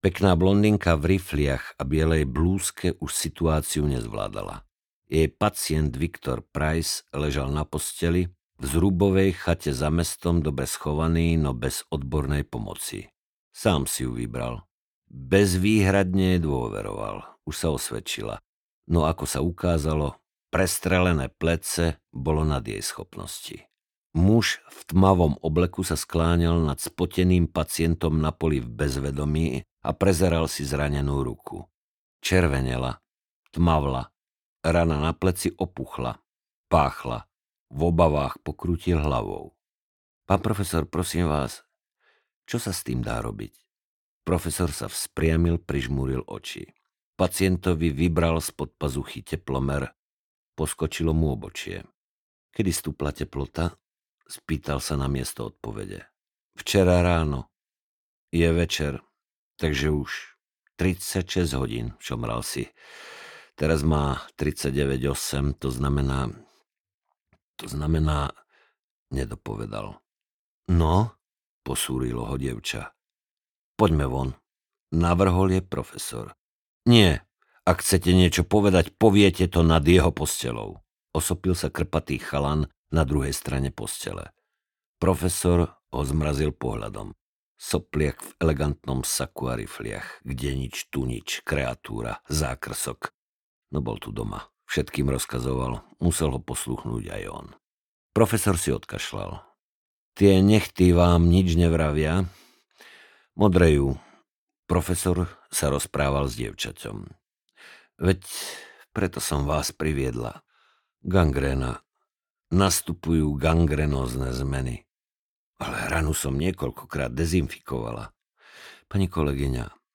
Korene zla audiokniha
Ukázka z knihy
• InterpretMarián Geišberg